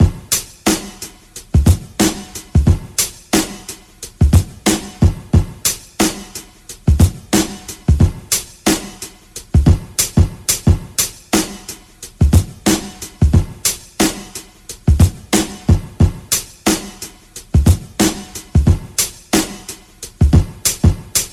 Loops, breaks